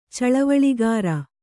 ♪ caḷavaḷigāra